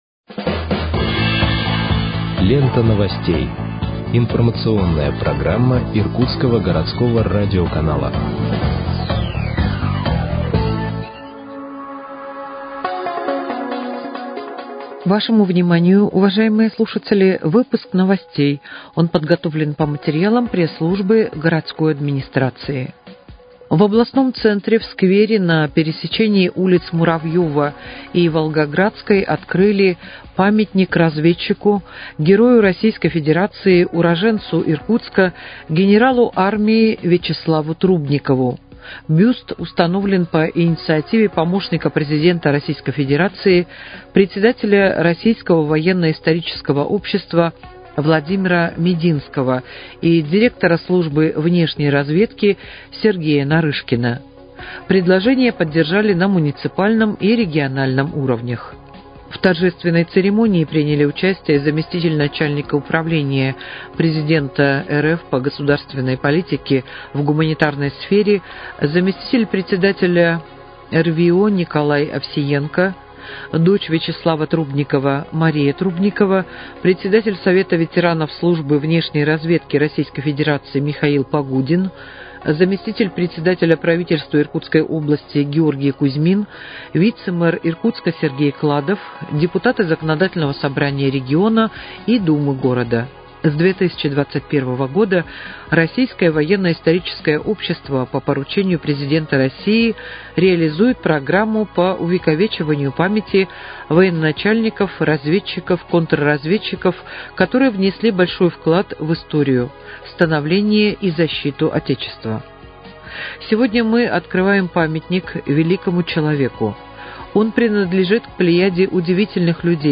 Выпуск новостей в подкастах газеты «Иркутск» от 11.12.2024 № 1